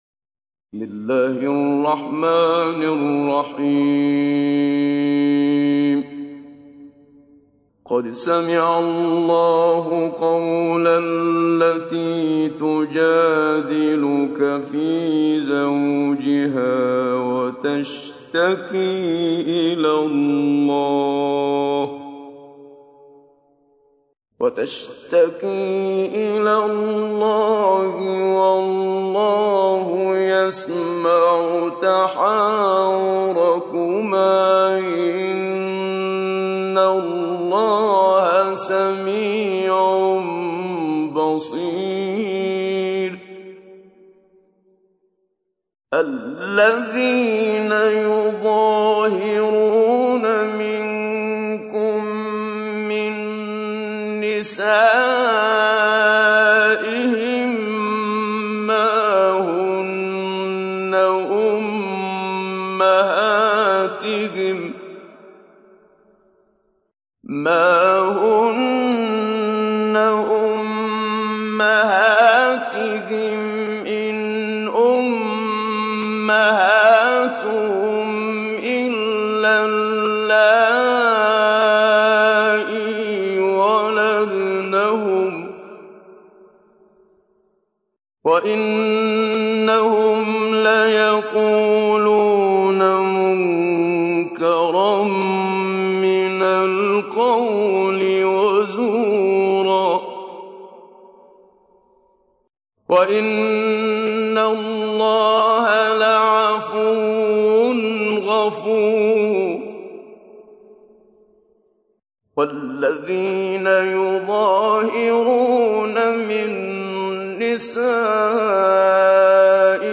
تحميل : 58. سورة المجادلة / القارئ محمد صديق المنشاوي / القرآن الكريم / موقع يا حسين